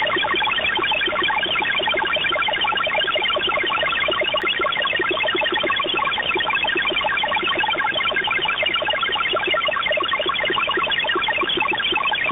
File:CIS MFSK 21-13 13-part fast.ogg - Signal Identification Wiki
MFSKMultiple Frequency Shift-Keying-21-13 signal, MFSKMultiple Frequency Shift-Keying-13 fast part